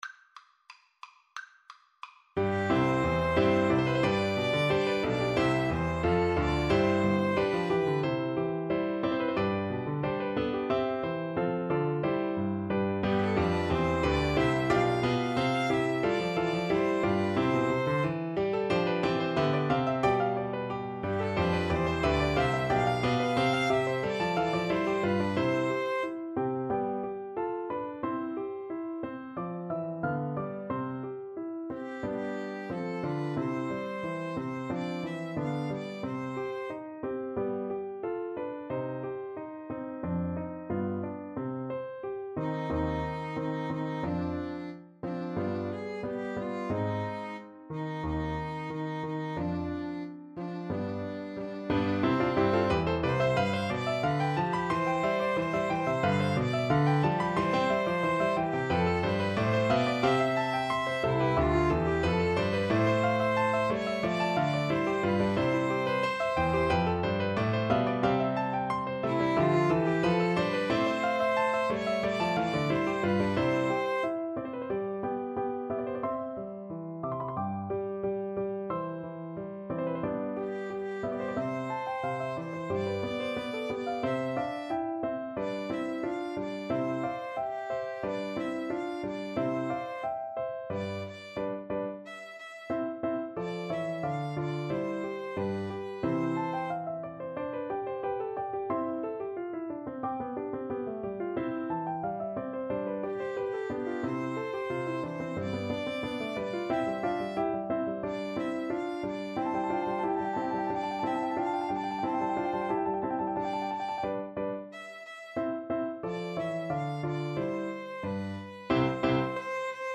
Allegro =180 (View more music marked Allegro)
4/4 (View more 4/4 Music)
Classical (View more Classical Piano Trio Music)